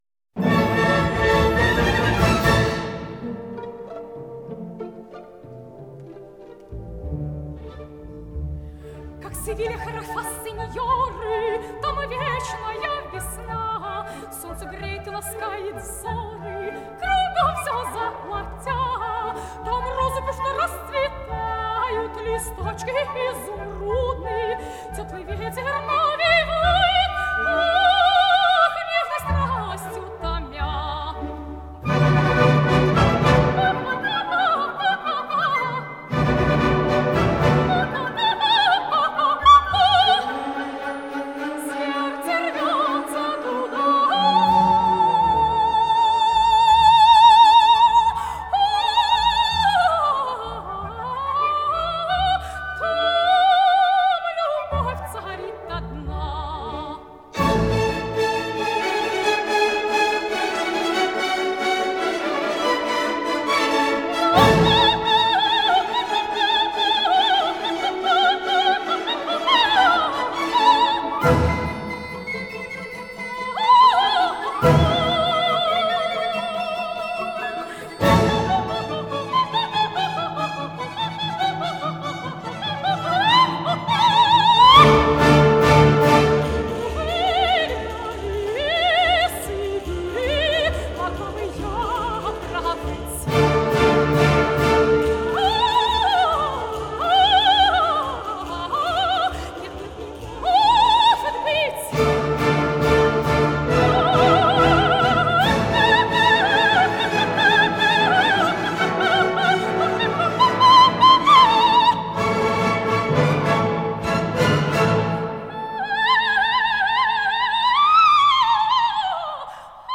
Галина Ковалёва. Севильяна из оперы Массне "Дон Сезар де Базан"